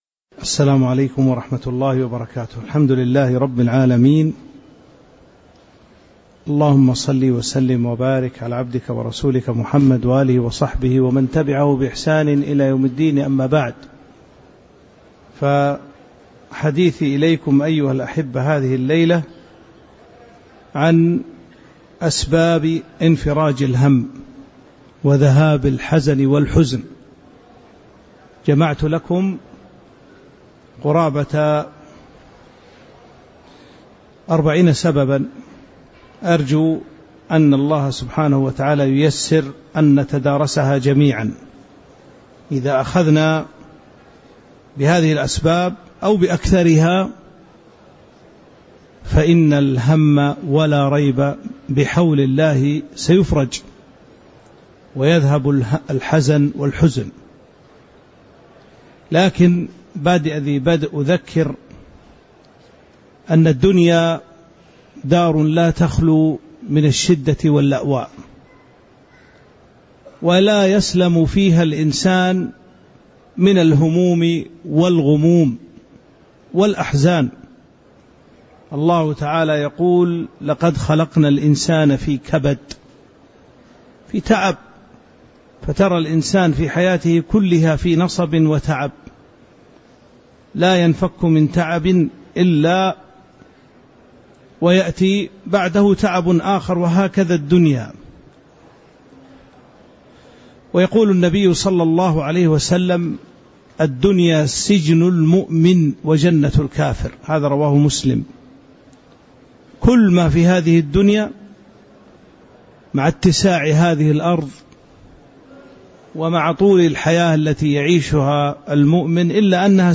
تاريخ النشر ١٨ ذو القعدة ١٤٤٥ هـ المكان: المسجد النبوي الشيخ